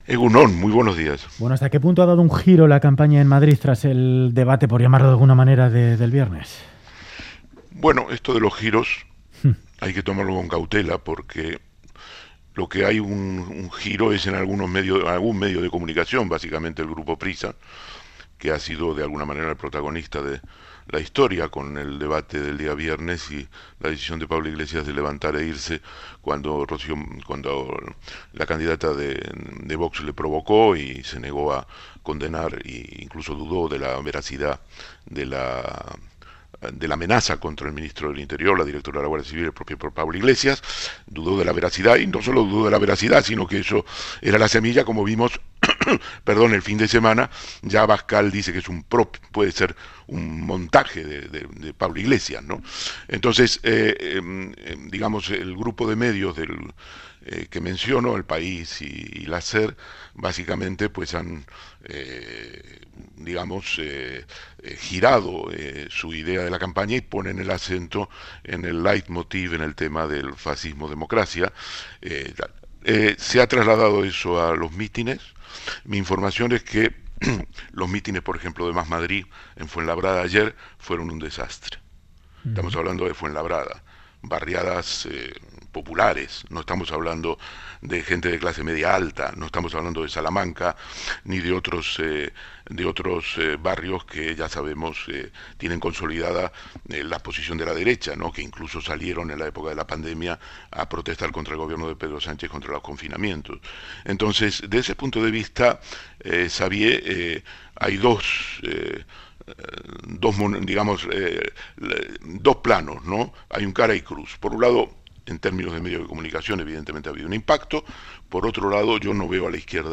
Audio: El periodista Ernesto Ekaizer analiza en Radio Euskadi el momento político en Madrid.